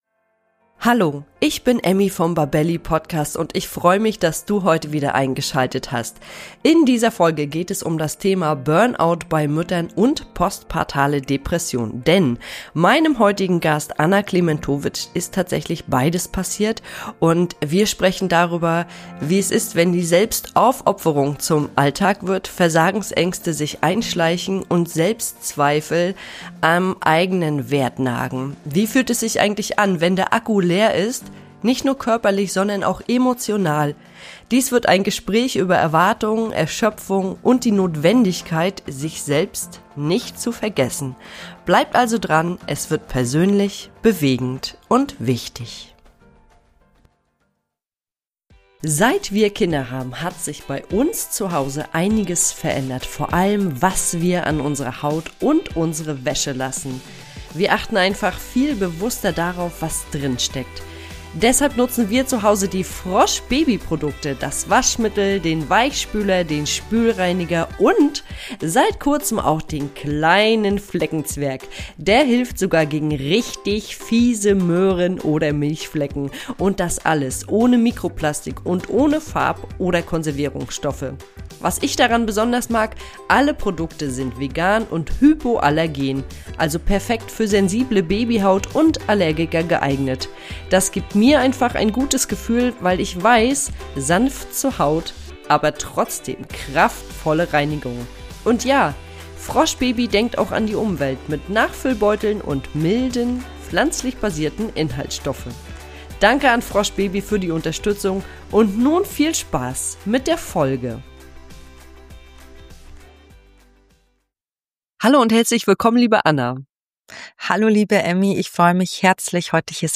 Ein ehrliches Gespräch über psychische Gesundheit, Mutterschaft und den Wert, offen über das zu sprechen, was uns überfordert.